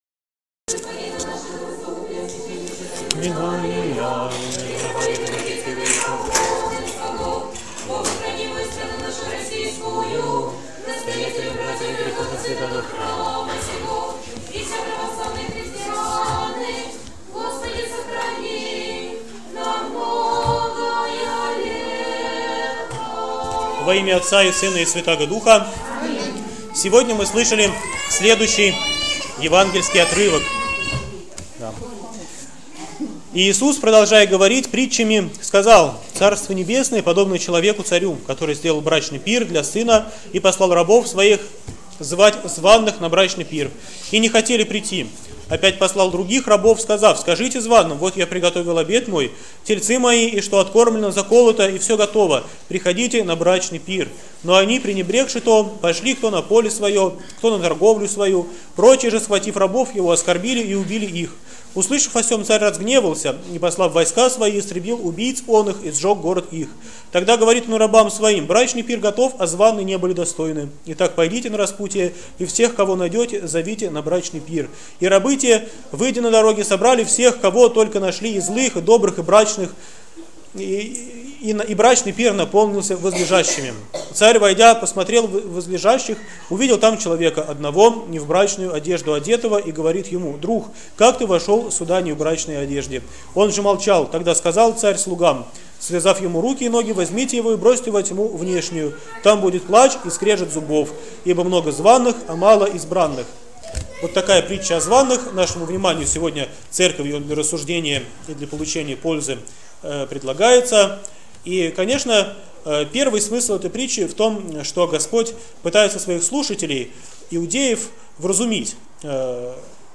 БОГОЛЮБСКИЙ ХРАМ ПОСЕЛОК ДУБРОВСКИЙ
ПРОПОВЕДЬ В НЕДЕЛЮ 14-Ю ПО ПЯТИДЕСЯТНИЦЕ